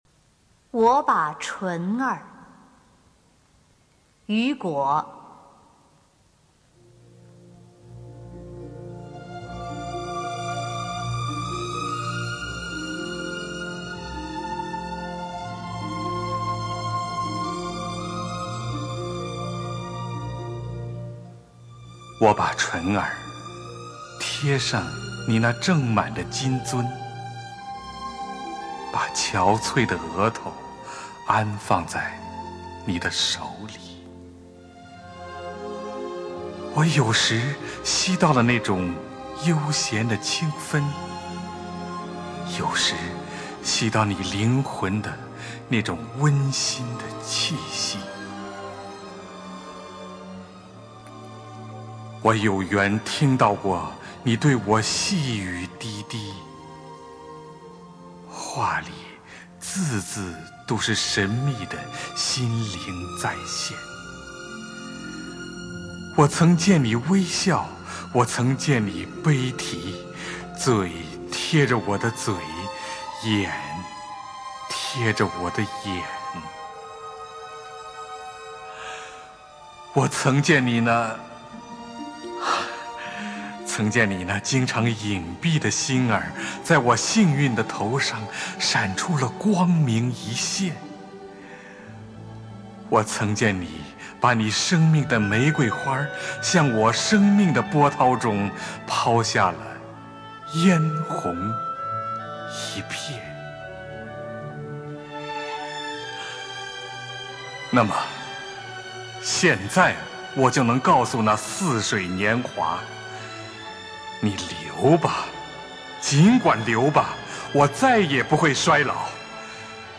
首页 视听 经典朗诵欣赏 丁建华、乔榛：外国爱情诗配乐朗诵